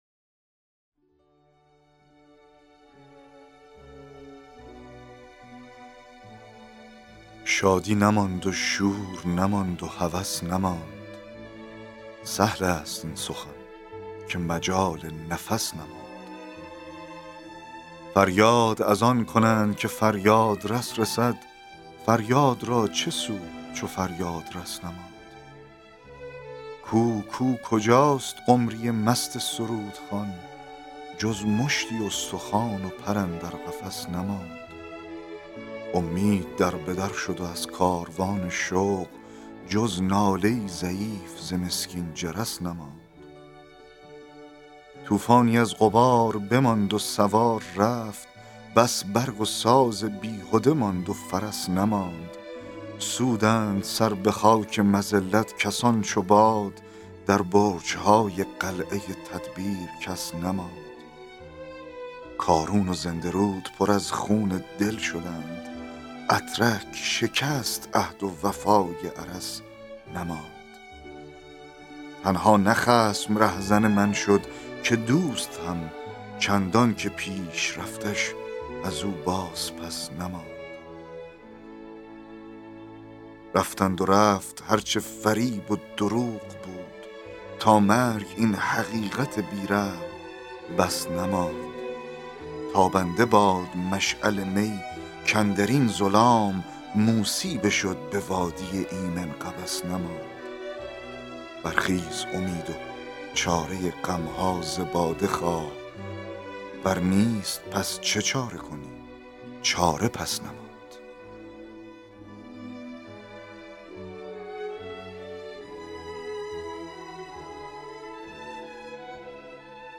دکلمه و تحلیل شعر, شعرهای اخوان شعر